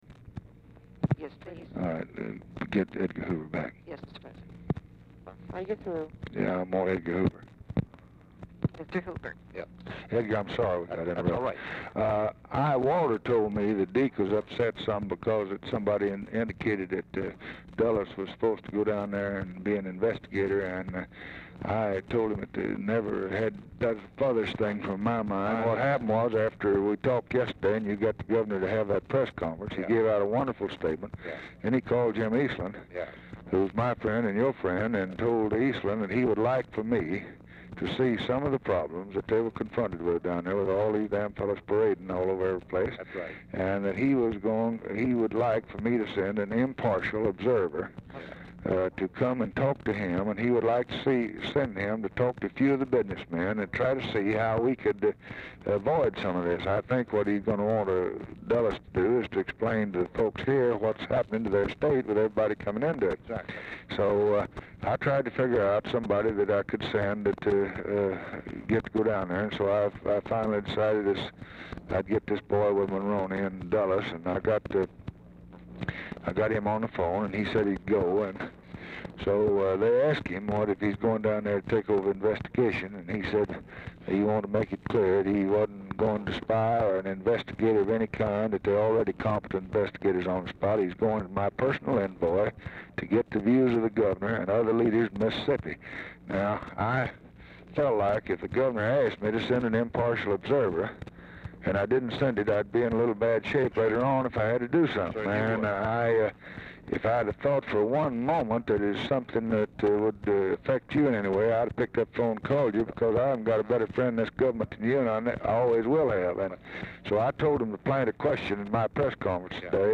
Telephone conversation # 3893, sound recording, LBJ and J. EDGAR HOOVER, 6/24/1964, time unknown | Discover LBJ
Format Dictation belt
Specific Item Type Telephone conversation